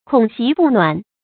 孔席不暖 注音： ㄎㄨㄙˇ ㄒㄧˊ ㄅㄨˋ ㄋㄨㄢˇ 讀音讀法： 意思解釋： 亦作「孔席不㈣」。